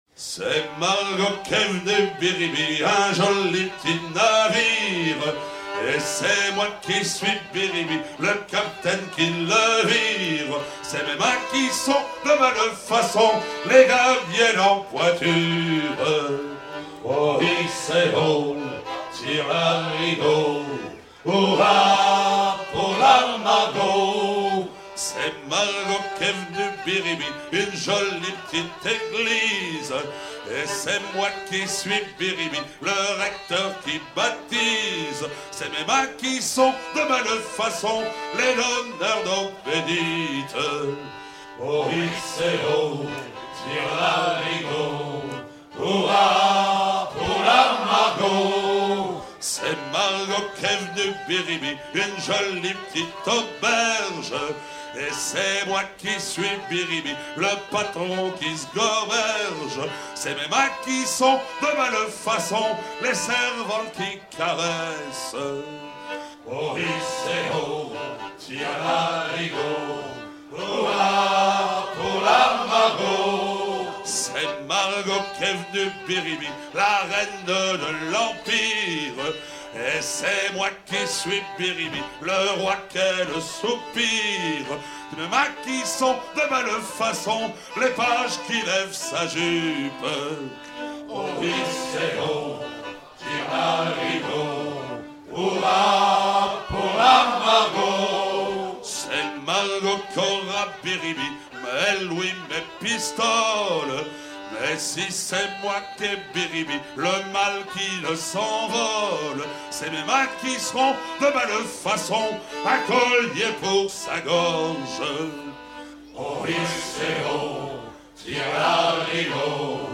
à virer au cabestan
circonstance : maritimes
Genre laisse
Pièce musicale éditée